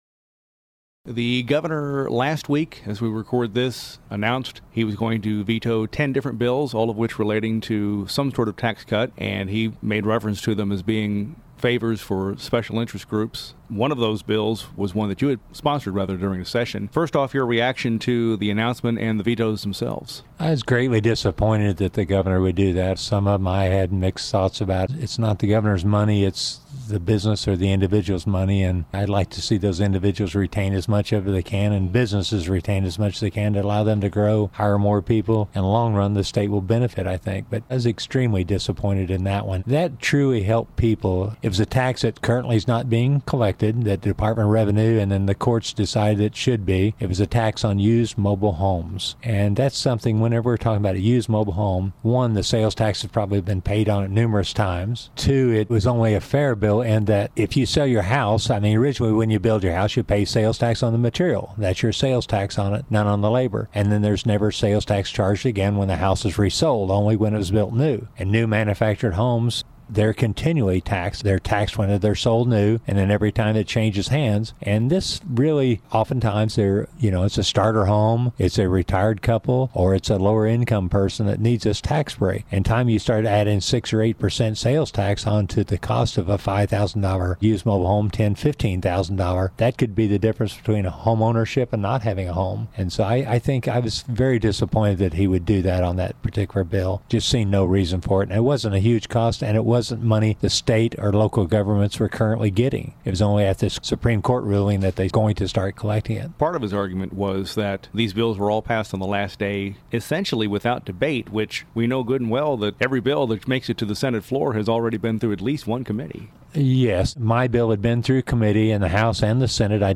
The audio below is a full-length interview with Sen. Cunningham — also available via podcast — for the week of June 16, 2014.